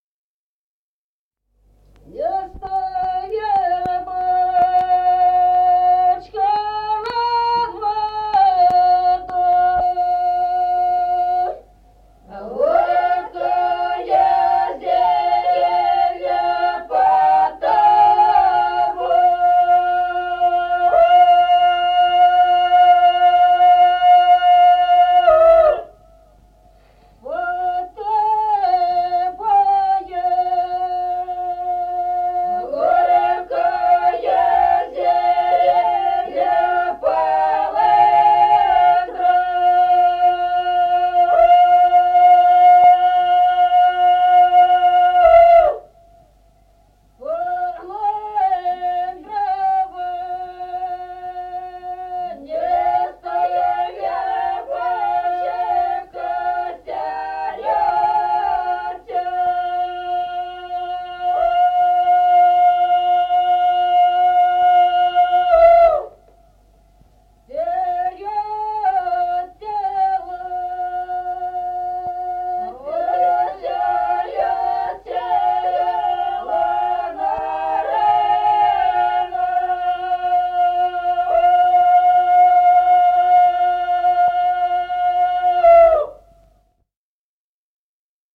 Народные песни Стародубского района «Не стой, вербочка», весняная девичья.
запев
подголосник
с. Остроглядово.